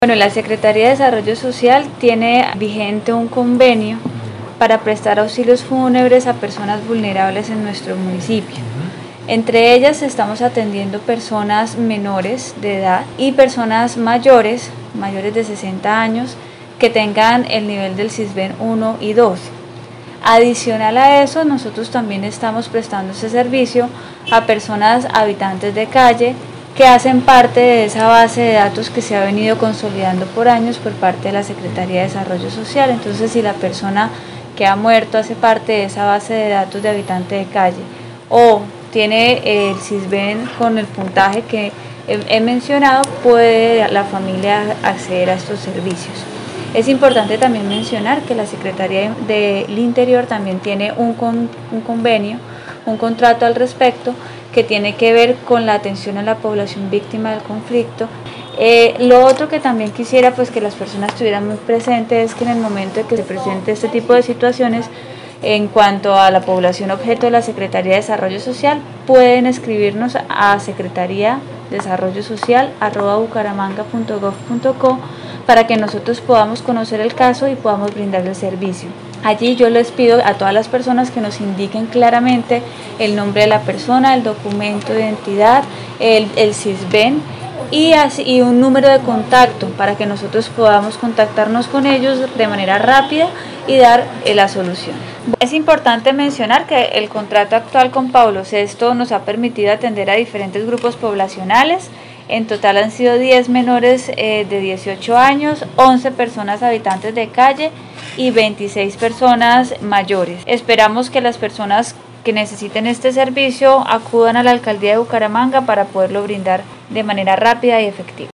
Descargar audio: Natalia Durán, secretaria de Desarrollo Social de Bucaramanga.
Natalia-Duran-secretaria-de-Desarrollo-Social-de-Bucaramanga.mp3